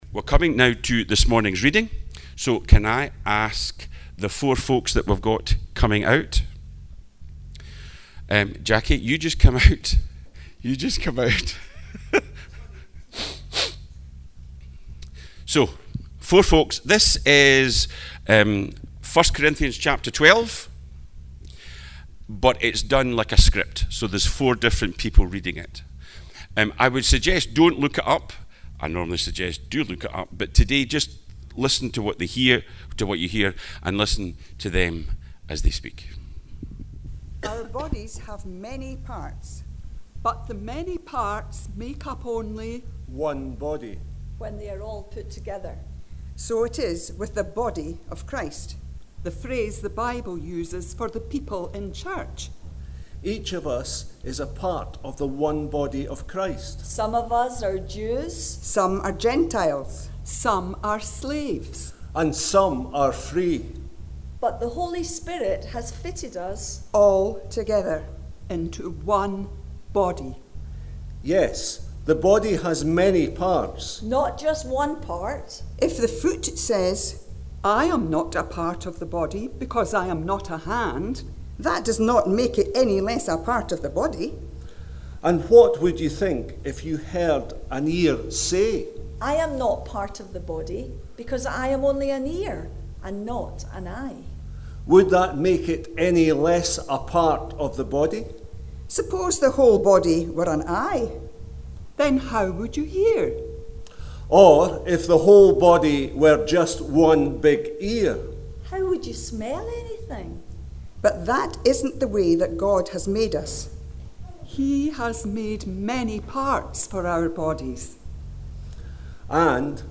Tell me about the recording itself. Teambuilders Holiday Club Family Service